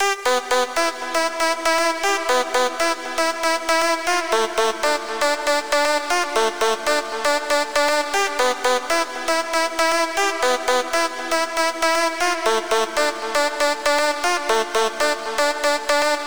• Essentials Key Lead 8 118 bpm.wav
SC_Essentials_Key_Lead_8_118_bpm_HKT_3qO.wav